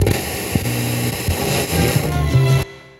80BPM RAD4-L.wav